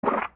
fart2.wav